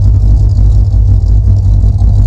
e49_idle.wav